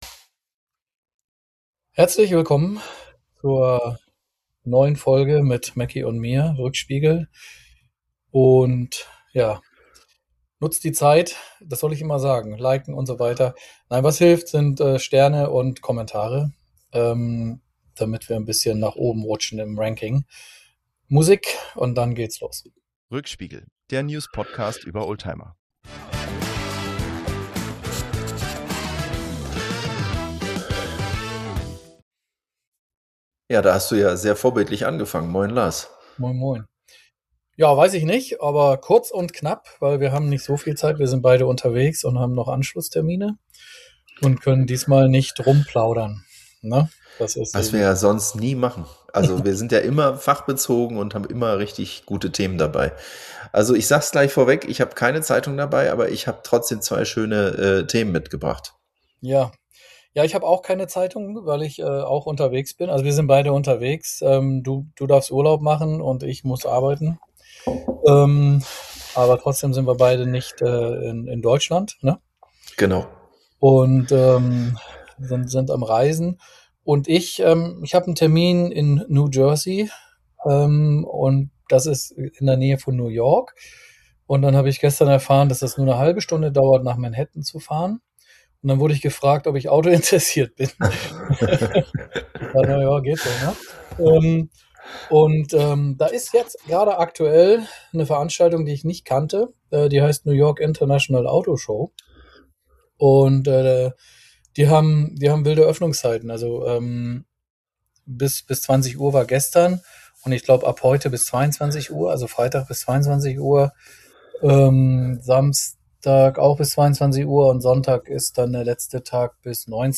Dabei geht es um internationale Hersteller, E-Auto-Parcours, Oldtimer, amerikanische Messekultur und die besondere Atmosphäre in Manhattan. Später wechselt das Gespräch zu Führerschein-Erinnerungen, Verkehrserziehung und seltenen bzw. besonders spannenden Fahrzeugen wie alten Datsun-Modellen, BMW-Neuheiten und dem Bugatti EB112. Insgesamt ist es eine lockere, persönliche Folge mit viel Auto-Nerdtalk und einigen humorvollen Abschweifungen.